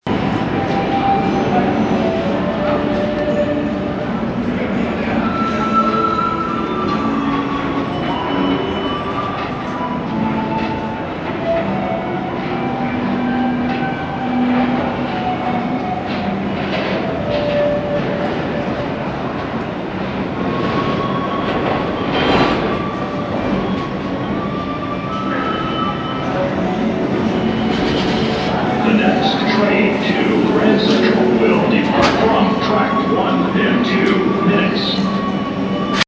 Subway S Train waiting area at 42nd St A man is playing the Pan Flute on the other side of the area, while people mull around, conversing. In the background, you can hear trains entering/leaving the station, echoing on the tracks. At the end of the clip, an automated voice announces the next train to Grand Central will arrive on track 1 in two minutes.
Station-Flutes2a.mp3